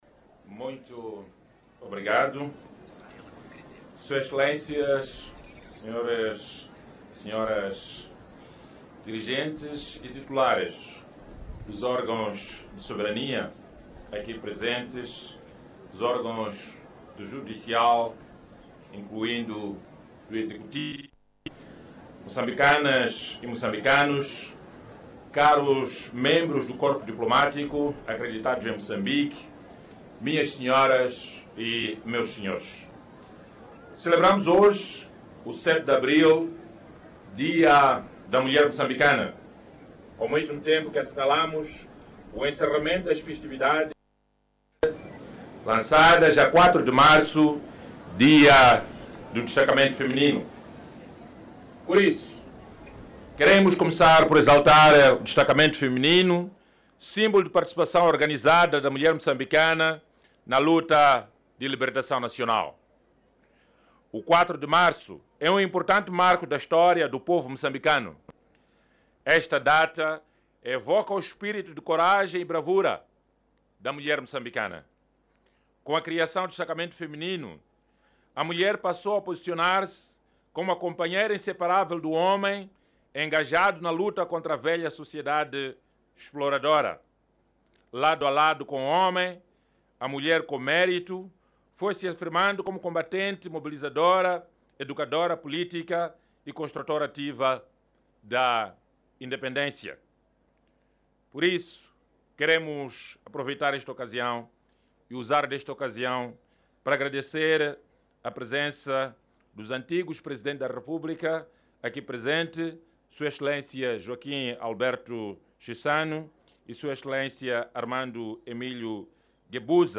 Discurso-do-PR-por-ocasiao-do-7-de-abril-Dia-da-Mulher-Mocambicana.mp3